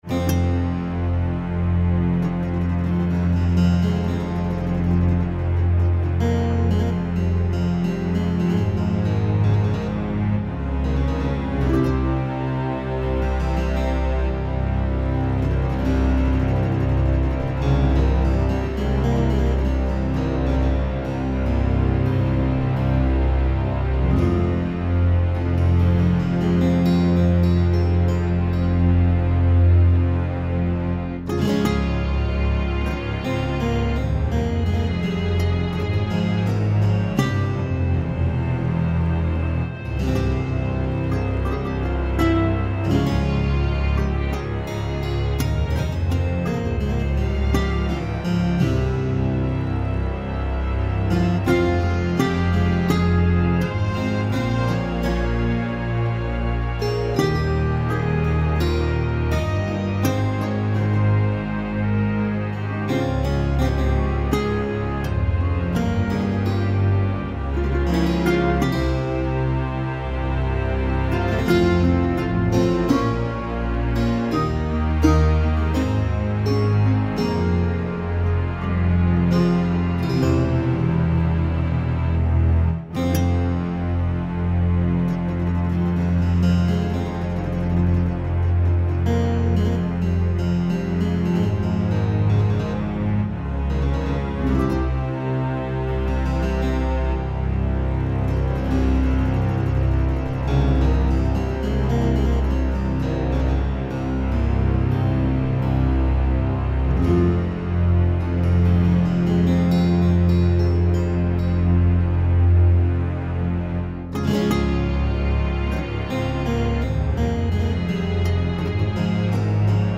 Largo [0-10] - - guitare - harpe - aerien - folk - melodieux
guitare - harpe - aerien - folk - melodieux